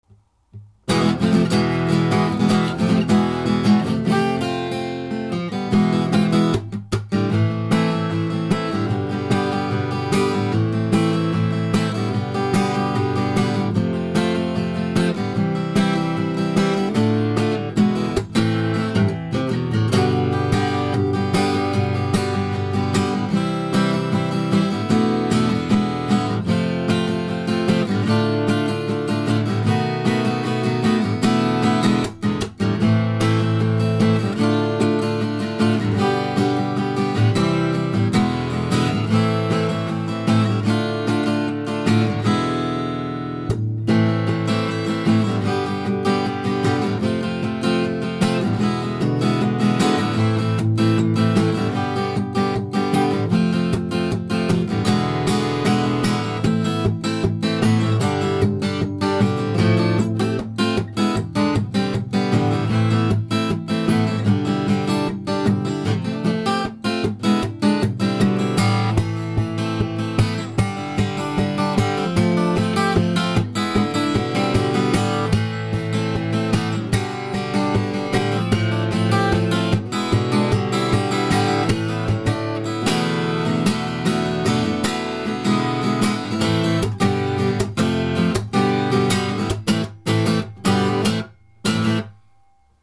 ヤマハのL-8 ８０’ｓジャパニーズビンテージを調整してみました。 スキャロップとノンスキャロップの違いを少し参考までに。